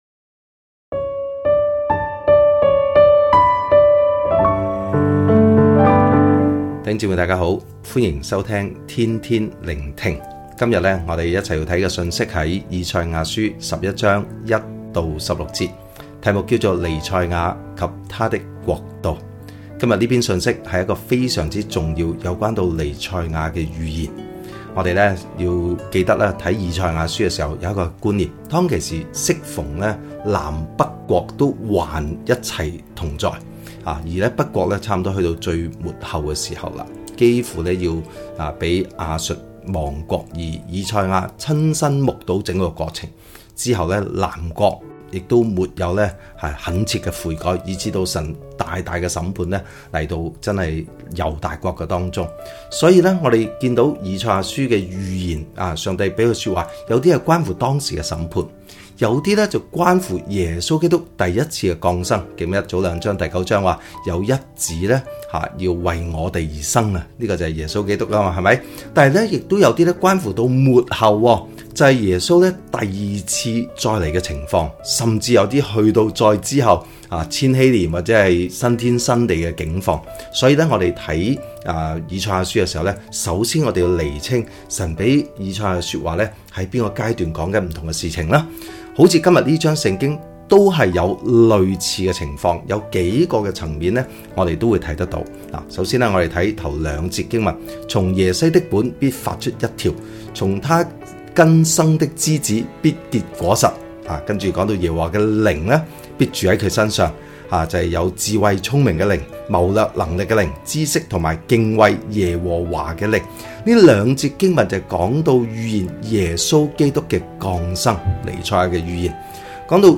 普通話錄音連結🔈